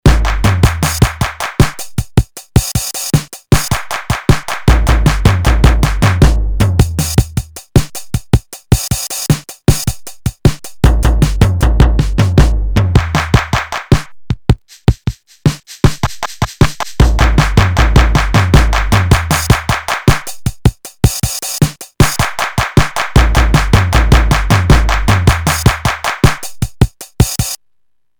Digital Drum Machine (1983)
edit WAVETABLE there are 18 sounds (including dynamic variations) on various EPROM memories coded at 8 bits with a companded compression.
SOUND LIST: kick (x3), snare (x3), hihats (x3), tom (x3), cymbal (x3), shaker (x2) and handclaps.
demo Supermix: Oberheim DX + synth SIEL CRUISE